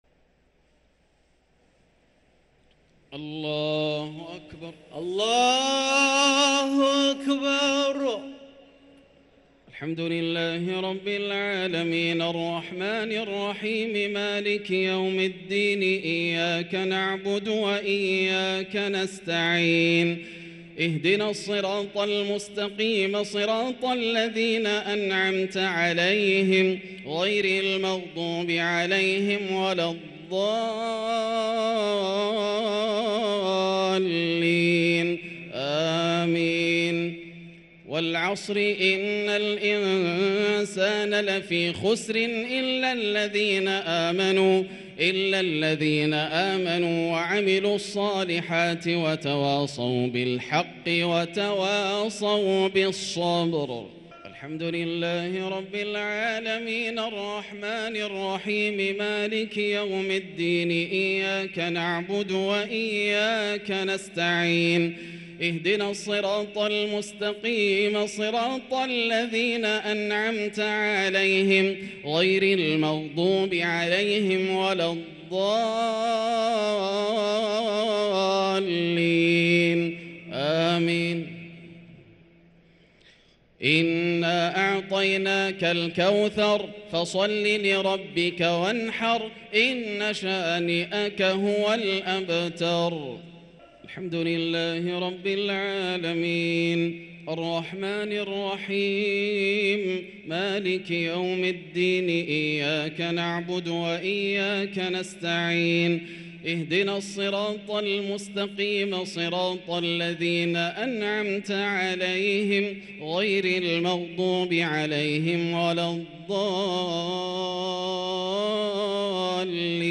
صلاة التراويح ليلة 6 رمضان 1444 للقارئ ياسر الدوسري - التسليمتان الأخيرتان صلاة التراويح